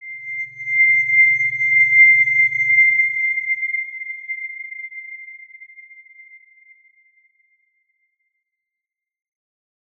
X_Windwistle-C6-pp.wav